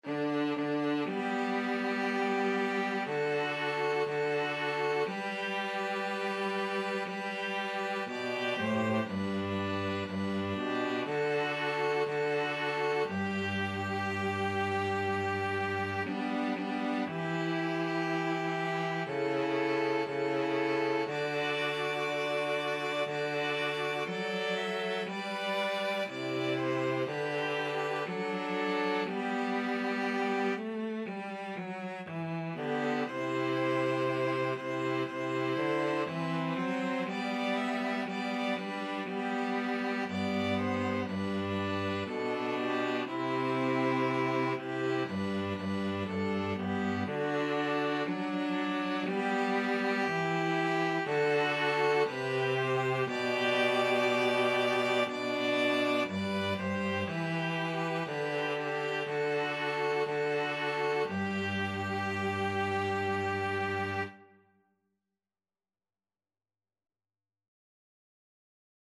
ViolinViolaCello
Slow =c.60
4/4 (View more 4/4 Music)
world (View more world String trio Music)